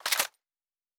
pgs/Assets/Audio/Sci-Fi Sounds/Weapons/Weapon 15 Foley 3.wav
Weapon 15 Foley 3.wav